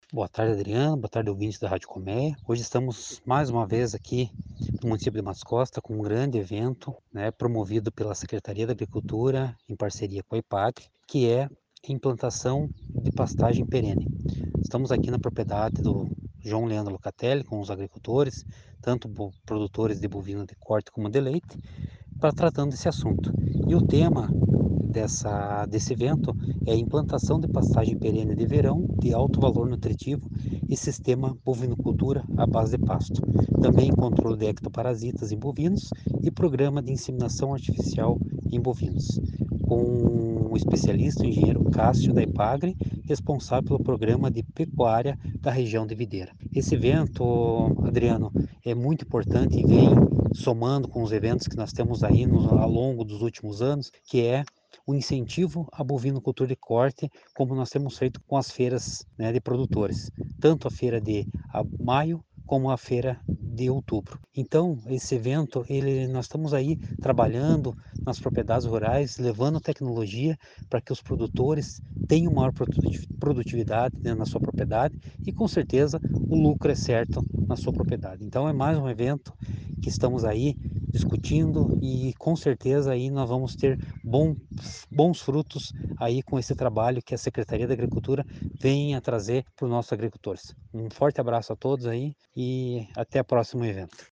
Acompanhe o áudio do chefe de gabinete da prefeitura: